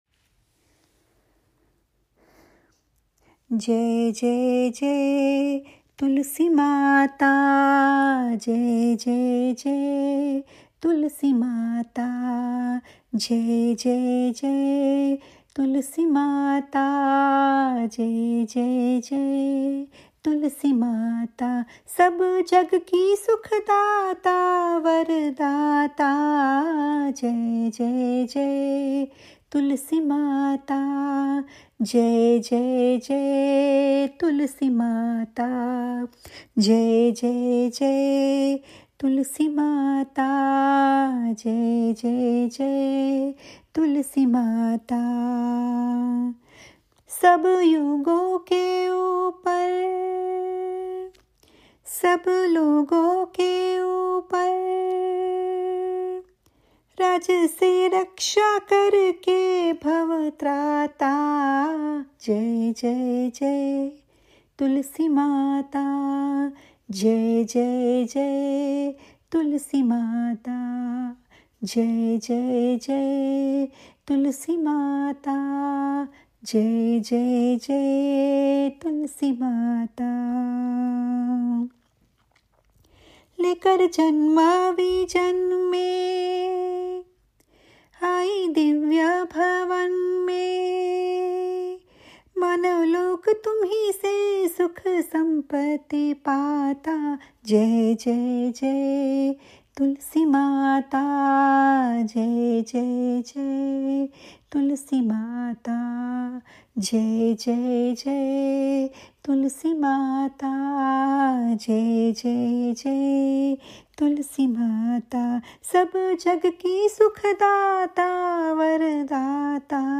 One more song to preserve ecology. It is a aarati song to the plant goddess Tulsimata.